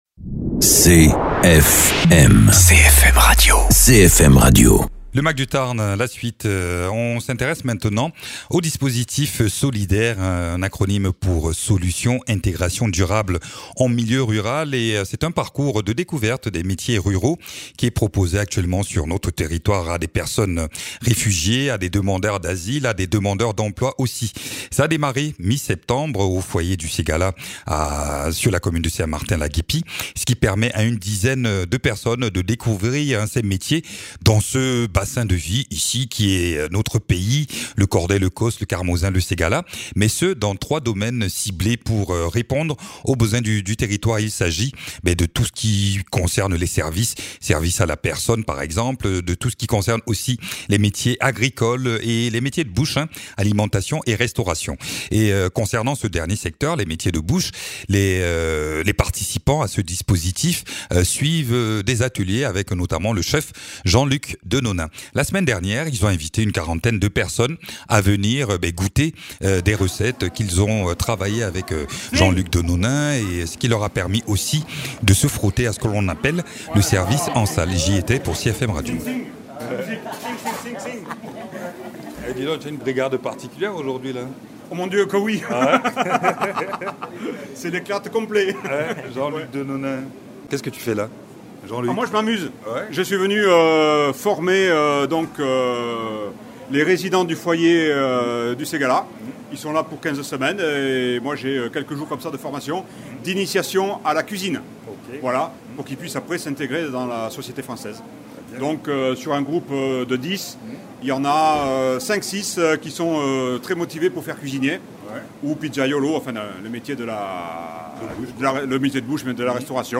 Interviews
Depuis la mi-septembre un groupe de réfugiés, de demandeurs d’asile et de demandeurs d’emploi sont accueillis au foyer du ségala pour participer au dispositif SOLID’R. L’idée étant de leur faire découvrir des métiers accessibles en zone rurale et plus particulièrement sur notre territoire et ce dans 3 secteurs : l’agriculture, les services à la personne et les métiers de bouche. Reportage lors d’un repas où ce groupe a participé à la préparation, cuisine et service.